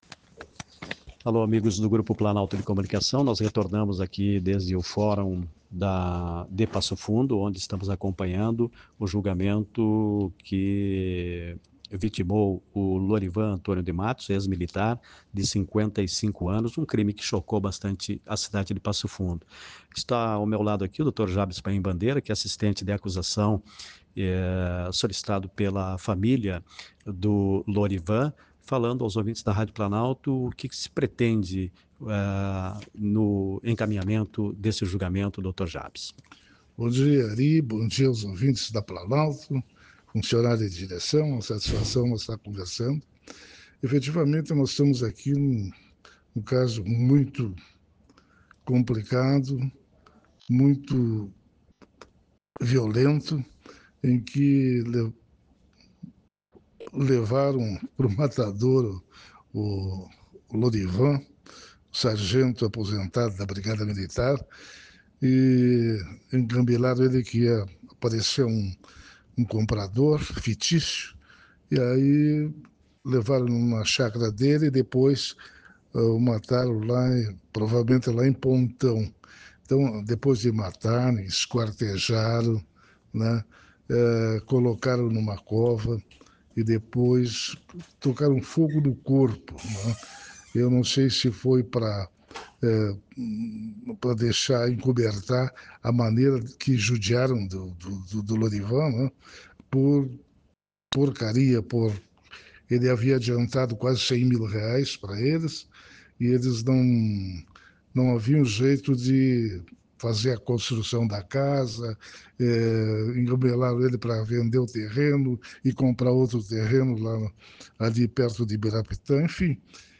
Trata-se de uma sessão do júri, com previsão de longa duração, tendo iniciado pela parte da manhã.